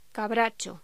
Locución: Cabracho